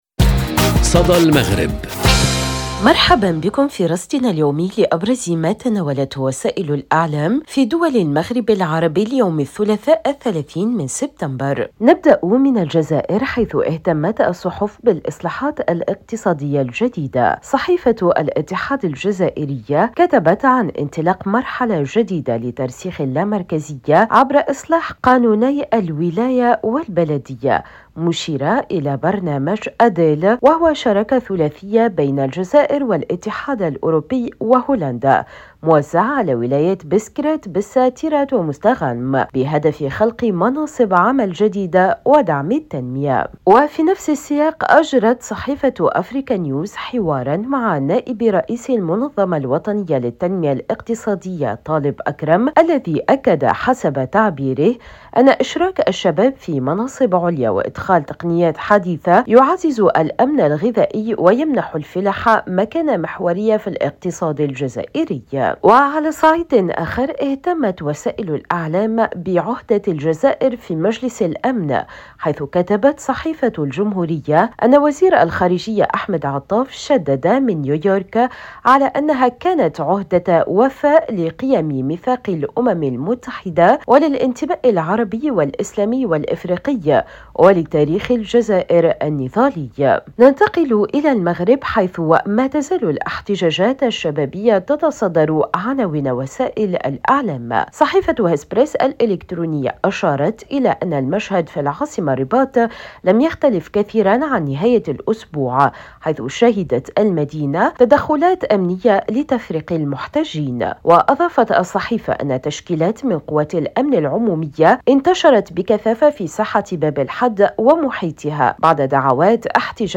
صدى المغرب برنامج إذاعي يومي يُبث عبر راديو أوريان إذاعة الشرق، يسلّط الضوء على أبرز ما تناولته وسائل الإعلام في دول المغرب العربي، بما في ذلك الصحف، القنوات التلفزية، والميديا الرقمية.